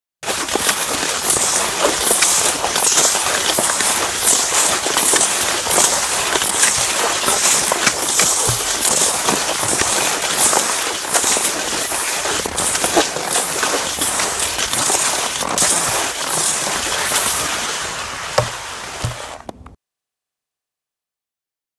Die Geräusche aus den Regionen Lausanne und Alpes vaudoises, haben Sie bestimmt alle erraten.
langlauf.wav